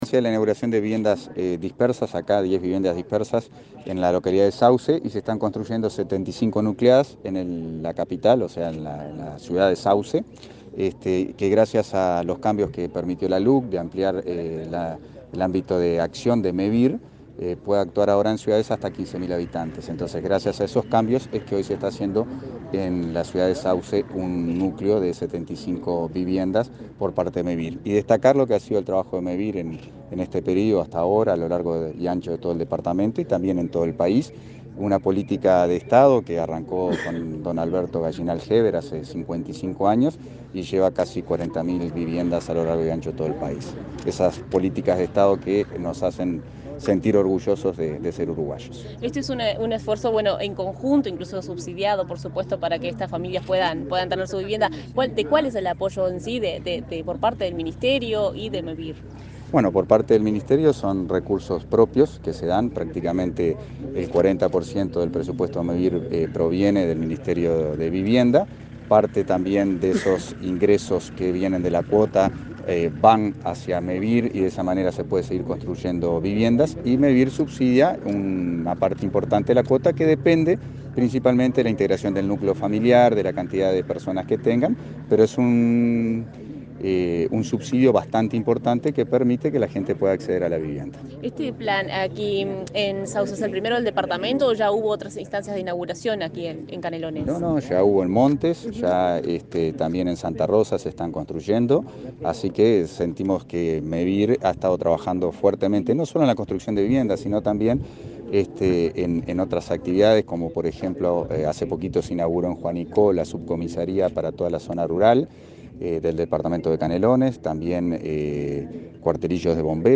Declaraciones a la prensa del subsecretario de Vivienda
Luego, Hackenbruch dialogó con la prensa.